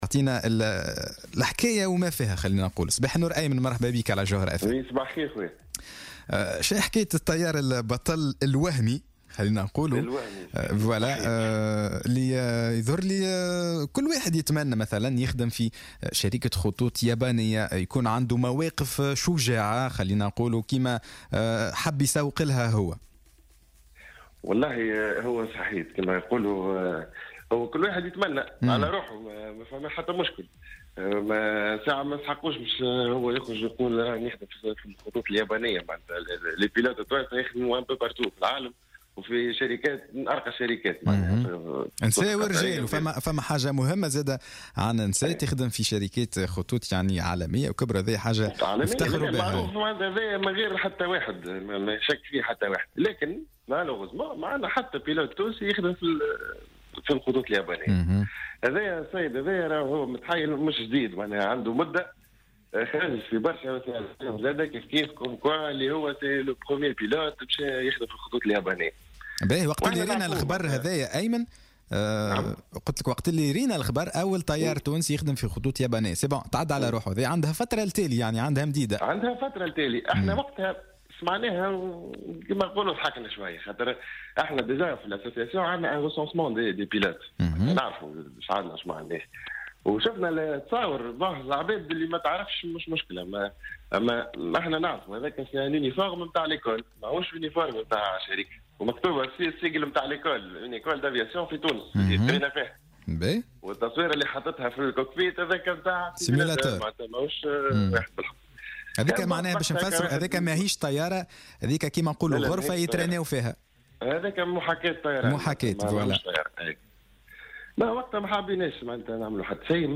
في مداخلة له على الجوهرة اف ام صباح...